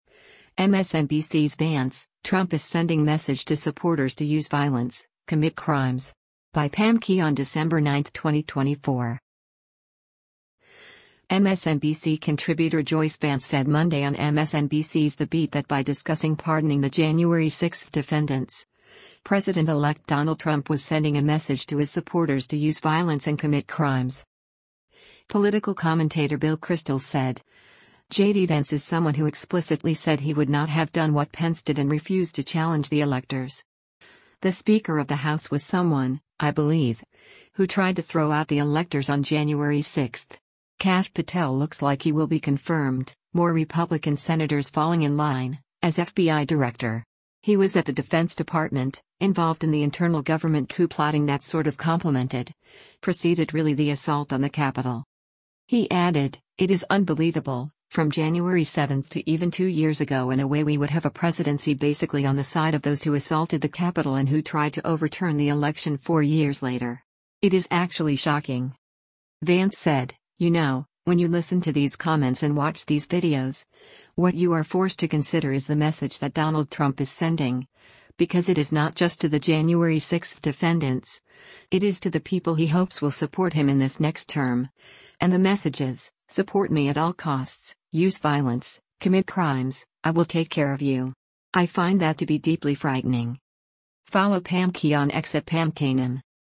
MSNBC contributor Joyce Vance said Monday on MSNBC’s “The Beat” that by discussing pardoning the January 6 defendants, President-elect Donald Trump was sending a message to his supporters to “use violence” and “commit crimes.”